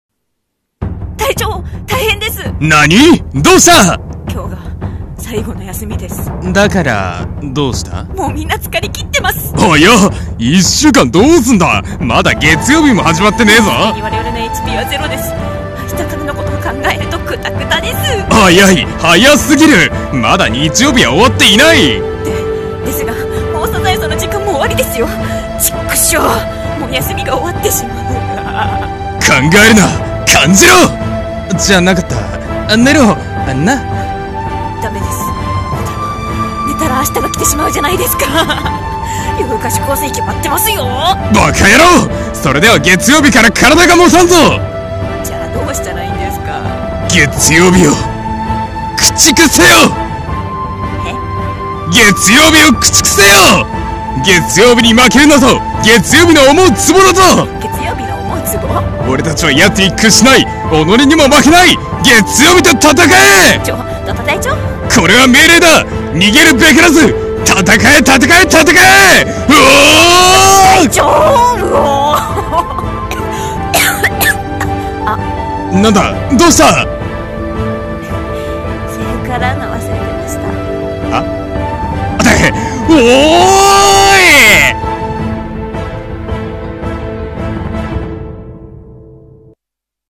【ギャグ声劇】月曜日を駆逐せよ！【掛け合い】